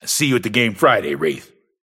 Infernus voice line - I see you at the game Friday, Wraith.